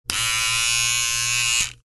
Звуки бритвы
Звук работы бритвы или эпилятора при включении и выключении